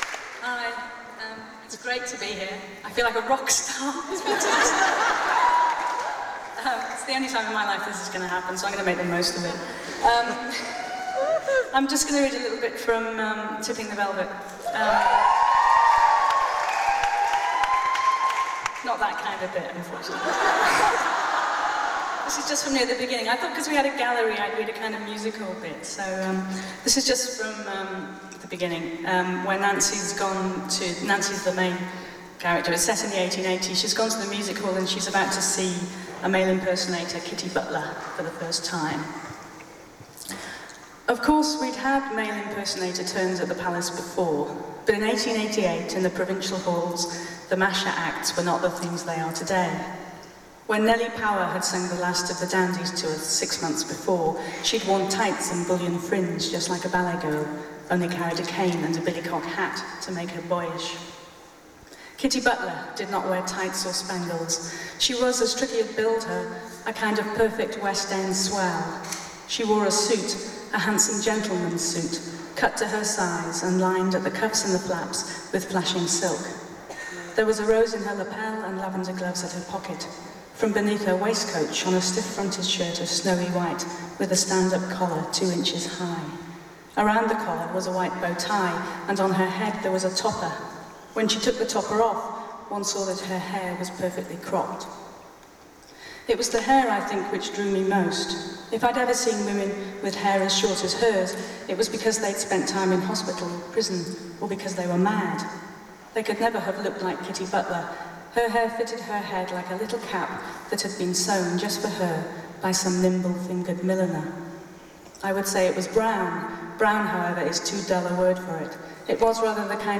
lifeblood: bootlegs: 2002-08-03: union chapel - london, england
02. reading from tipping the velvet - sarah waters (4:49)